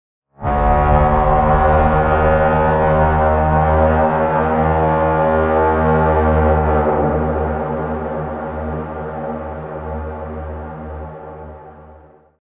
دانلود آهنگ شیپور جنگ 2 از افکت صوتی اشیاء
دانلود صدای شیپور جنگ 2 از ساعد نیوز با لینک مستقیم و کیفیت بالا
جلوه های صوتی